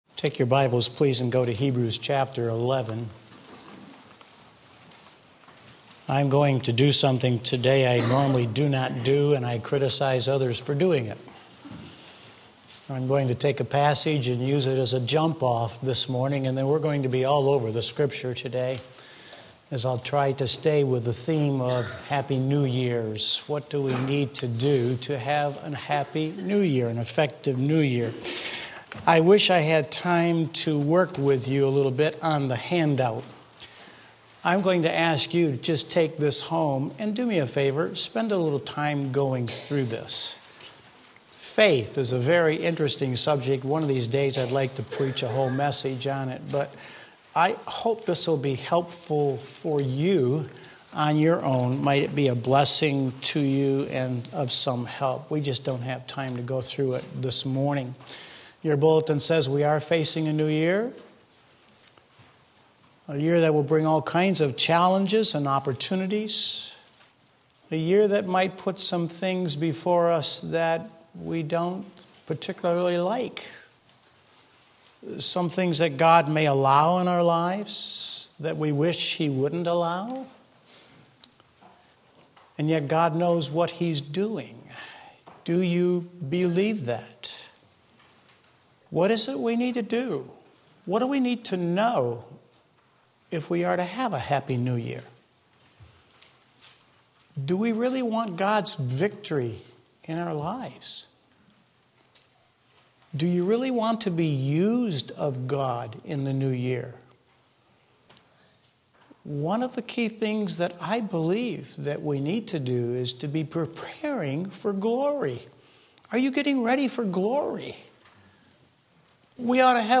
Sermon Link
Obtaining a Happy New Year Hebrews 11:32-34 Sunday Morning Service